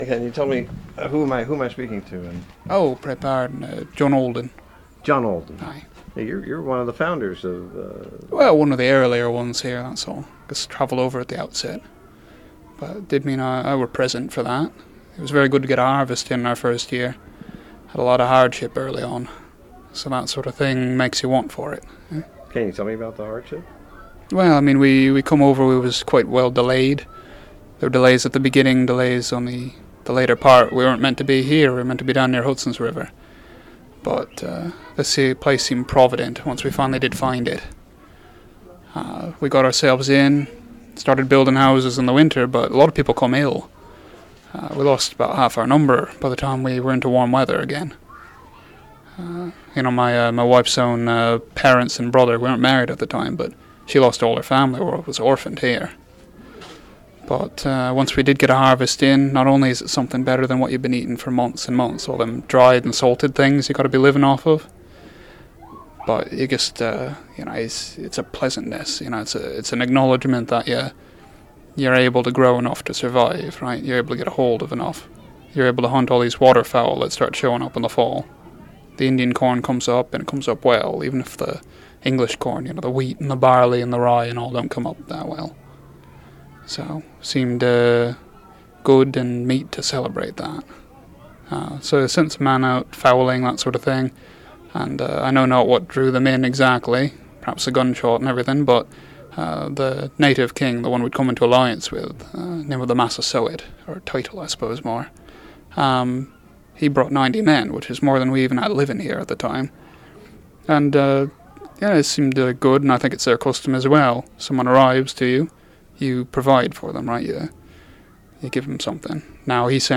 JOHN ALDEN, FORMERLY OF SOUTHAMPTON, IN THIS UNEDITED INTERVIEW, DESCRIBES THE UPS AND DOWNS OF TRYING TO GET SETTLED IN THE NEW WORLD.
JOHN-ALDEN-COMPLETE-INTERVIEW.mp3